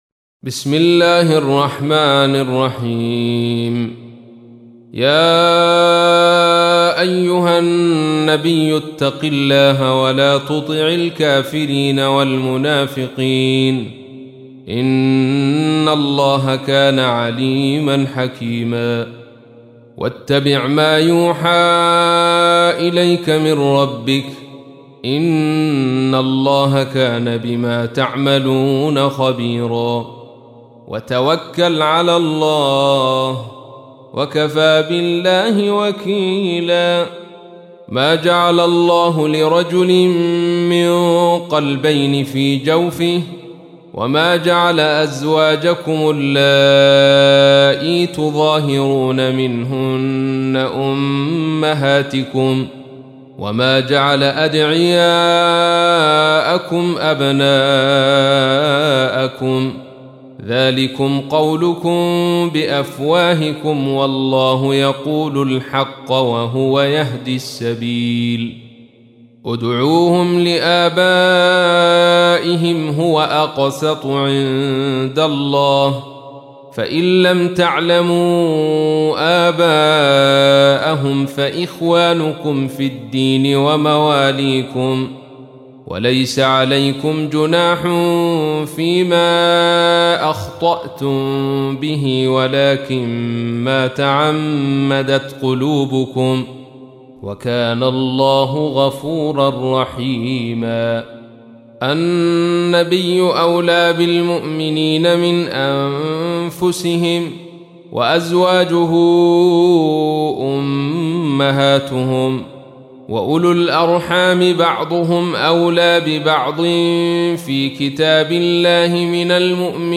تحميل : 33. سورة الأحزاب / القارئ عبد الرشيد صوفي / القرآن الكريم / موقع يا حسين